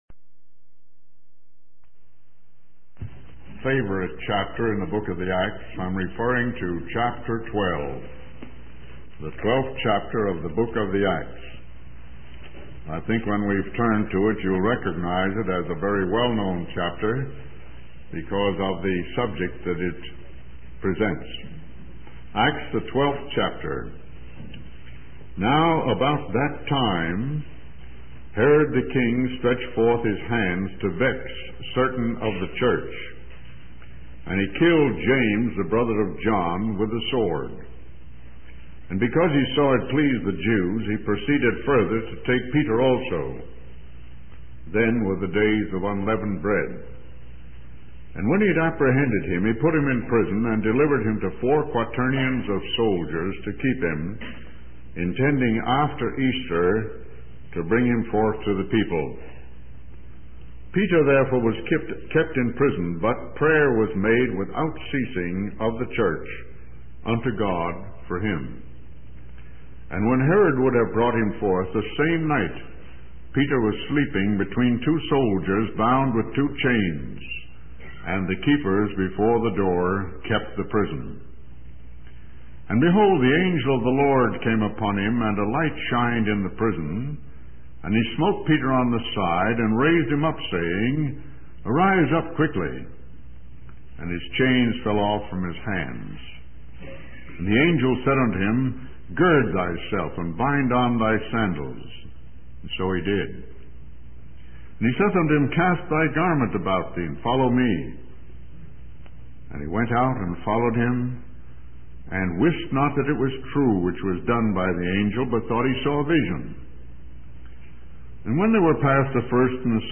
In this sermon, the preacher emphasizes the danger of pride and how it can lead to destruction. He uses the example of the king of Babylon who boasted about his own greatness and was ultimately brought down.